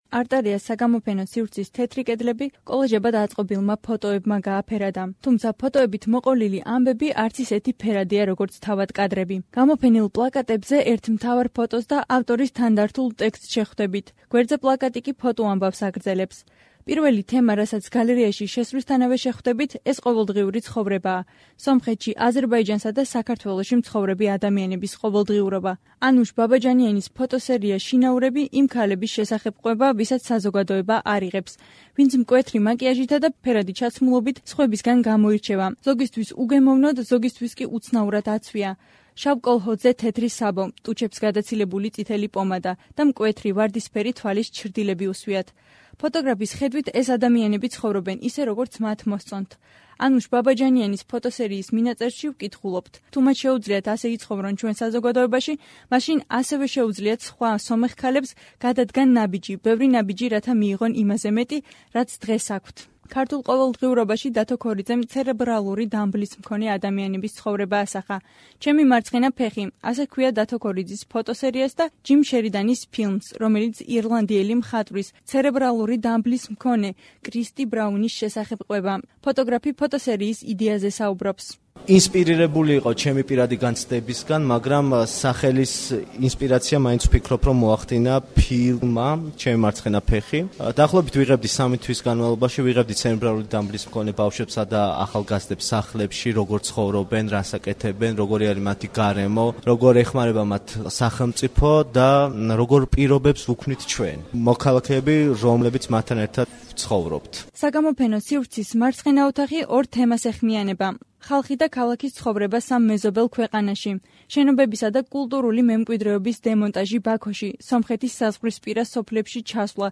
ცერებრალური დამბლის მქონე კრისტი ბრაუნის შესახებ ჰყვება. ფოტოგრაფი ფოტოსერიის იდეაზე ლაპარაკობს: